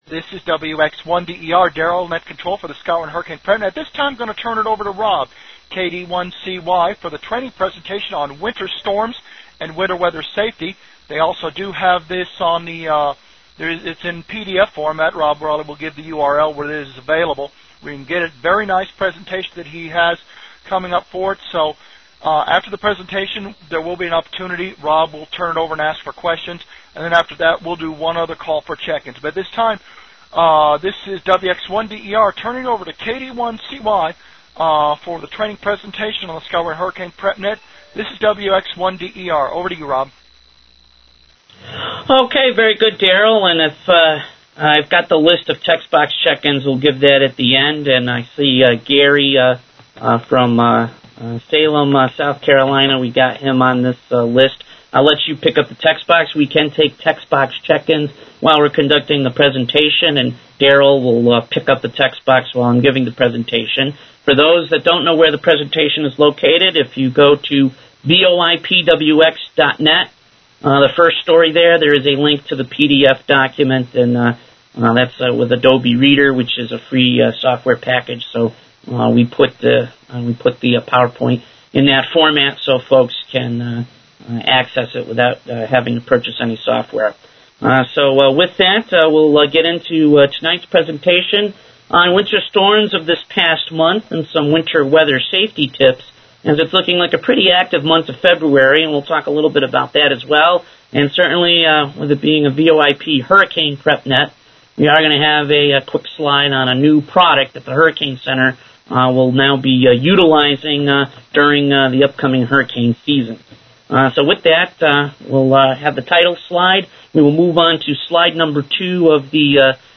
february_2014_winter_storms_eastern_us_presentation.mp3